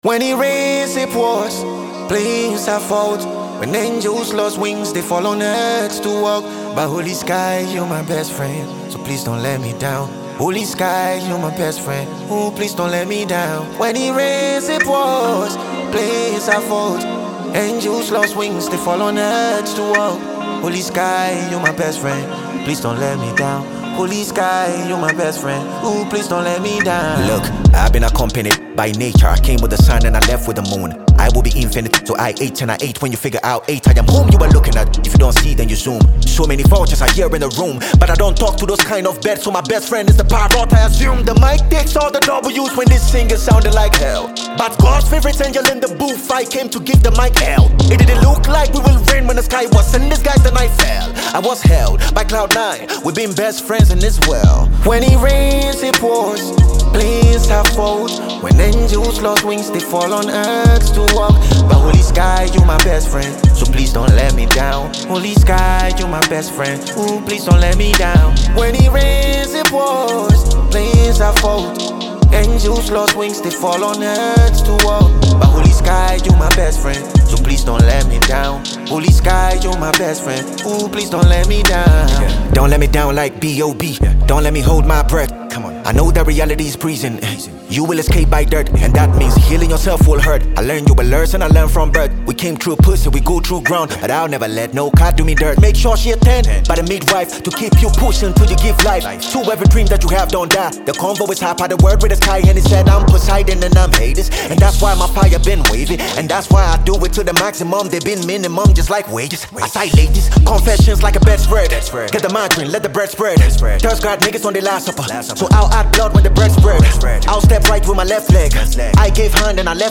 a hardcore Ghanaian rapper